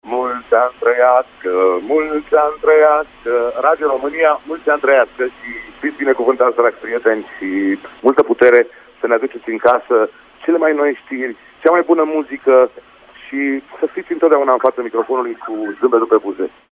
(Exclusiv/audio) Radio România 90: Bodo de la Proconsul cântă pentru noi "Mulți ani trăiască!"